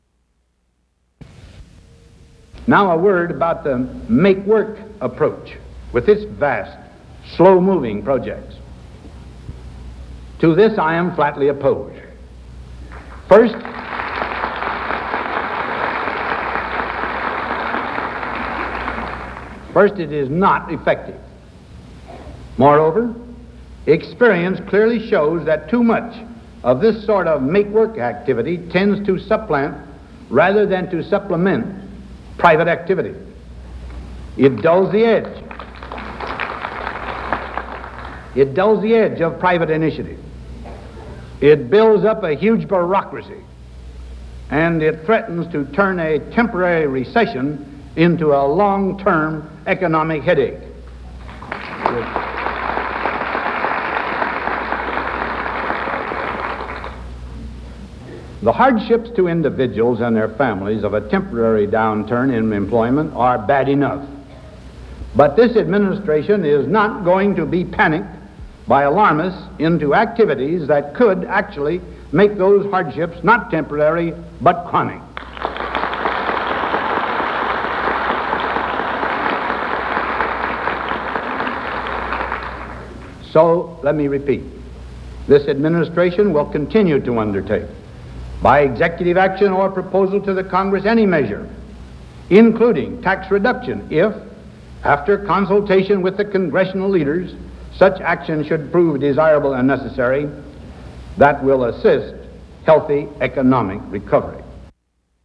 Excerpts of speech against new government work projects to aid the recession